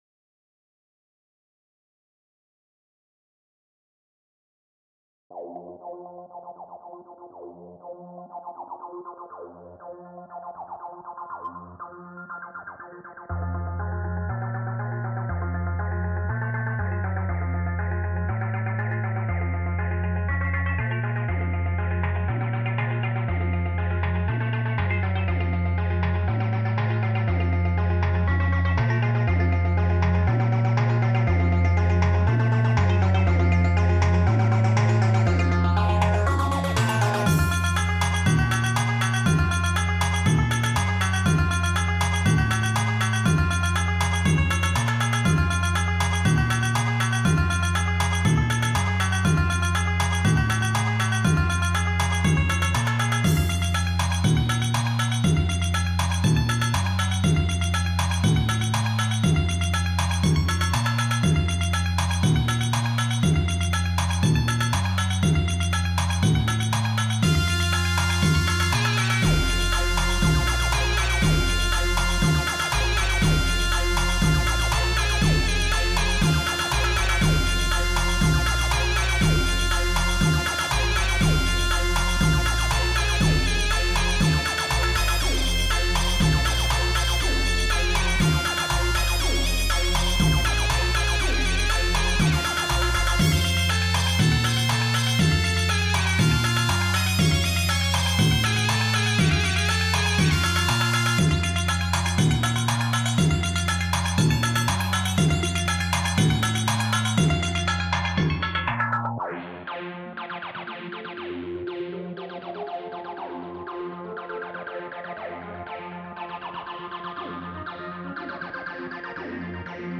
Baile Beats Dance